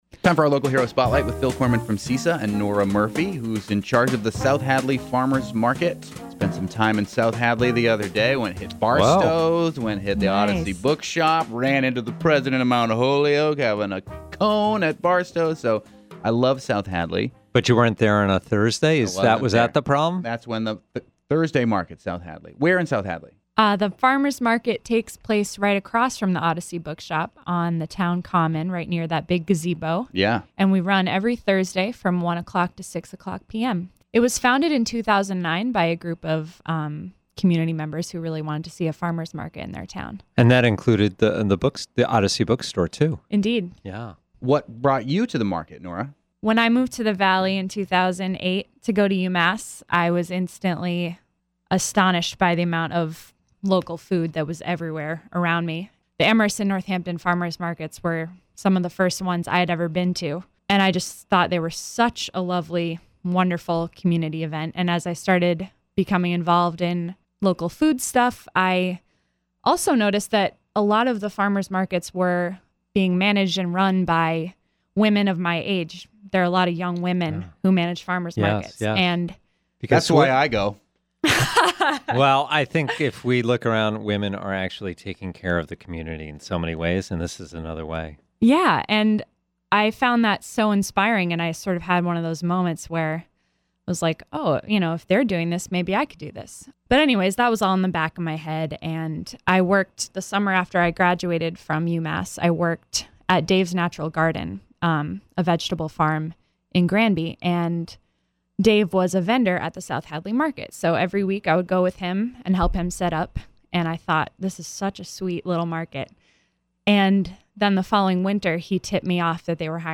Interview: South Hadley Farmers’ Market